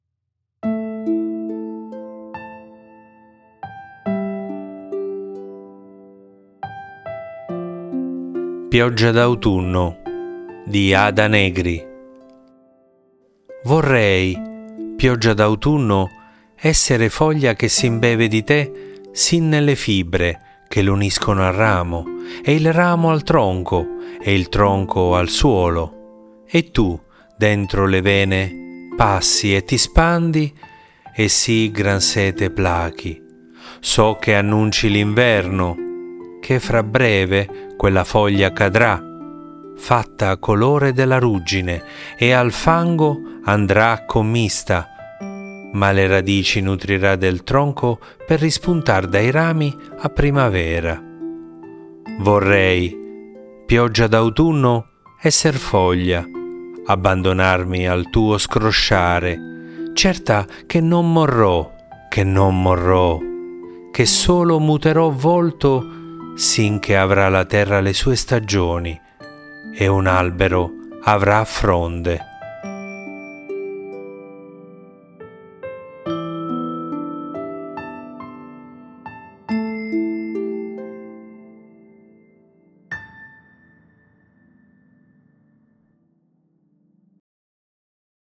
Leggi e ascolta la poesia “pioggia d’autunno” di Ada Negri